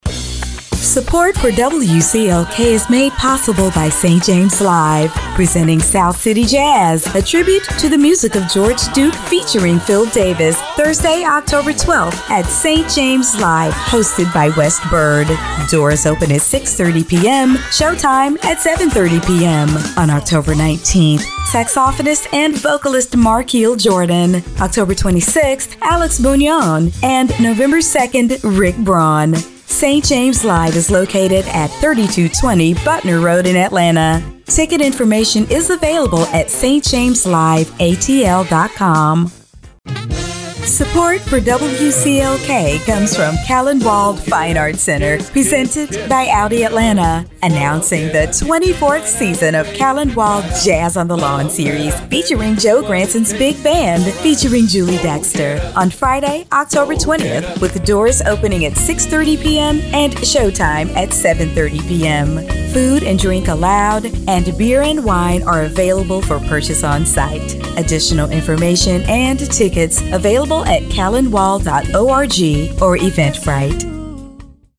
Female
English (North American)
Adult (30-50)
Conversational, authentic, smooth, unique, authoritative, friendly, humorous
Radio Commercials